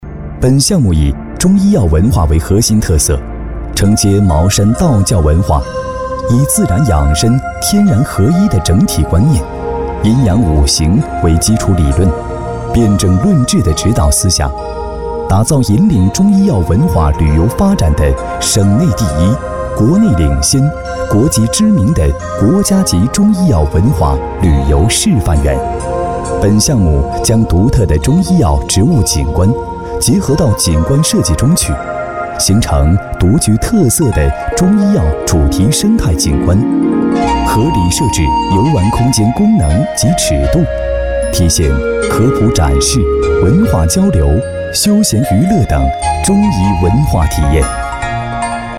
中医男92号（委婉讲述）
自然诉说 医疗专题
年轻时尚男音，大气沉稳。